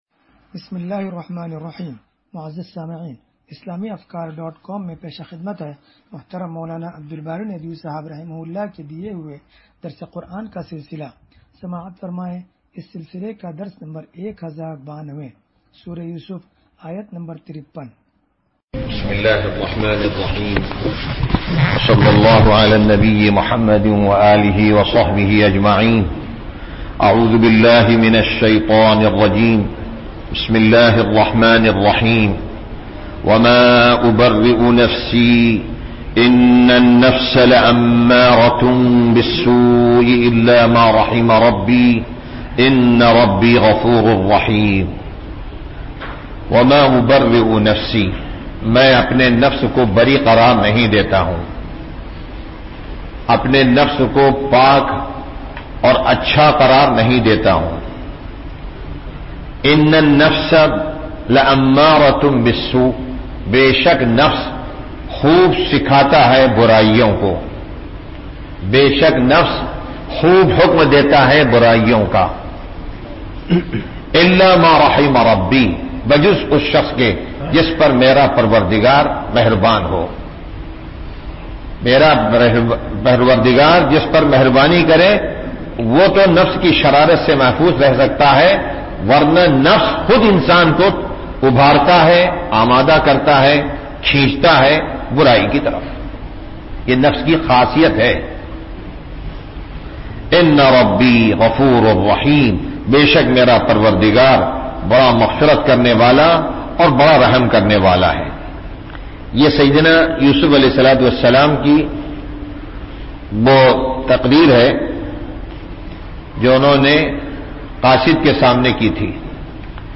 درس قرآن نمبر 1092
درس-قرآن-نمبر-1092.mp3